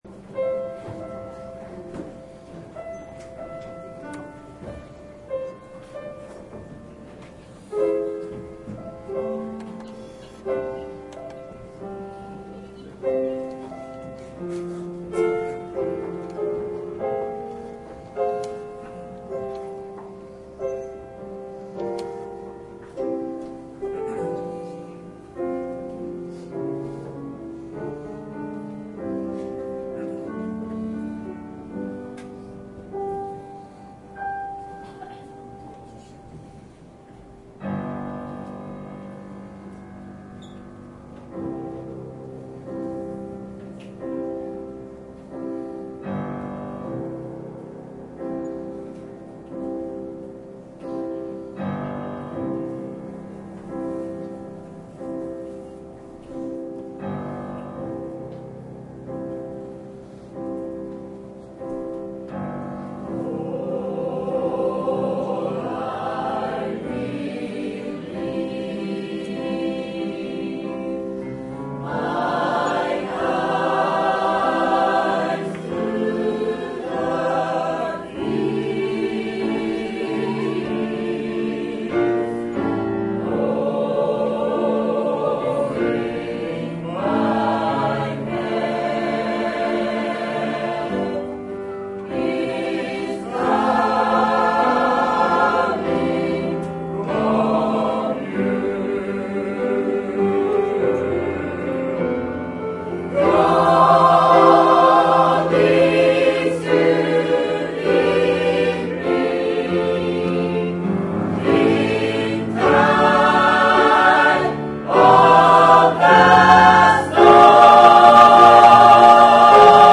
「ハレルヤ」と主に感謝し、みんなで賛美を捧げました
ゴスペルクワイヤー Play Download
arigatou2010gospel.mp3